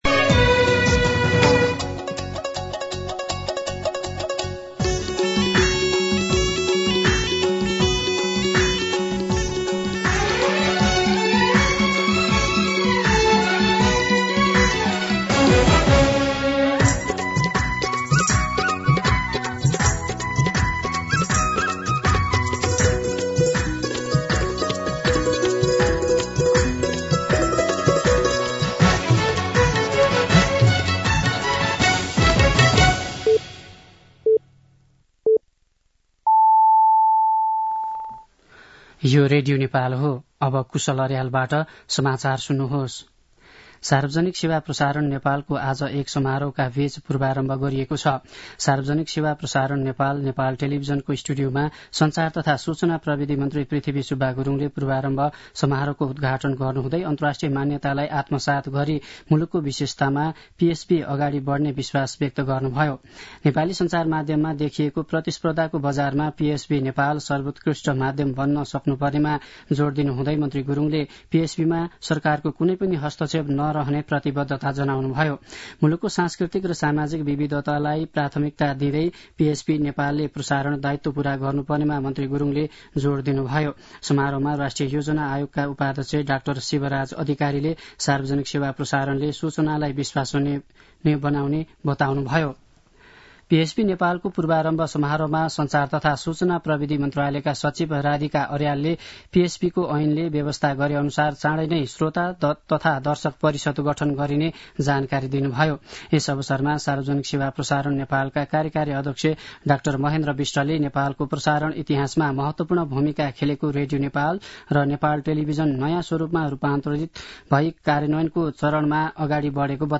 दिउँसो ४ बजेको नेपाली समाचार : २ माघ , २०८१
4pm-News.mp3